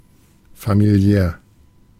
Ääntäminen
Synonyymit domestique familier Ääntäminen France: IPA: [fa.mi.ljal] Haettu sana löytyi näillä lähdekielillä: ranska Käännös Ääninäyte Adjektiivit 1. familiär Muut/tuntemattomat 2.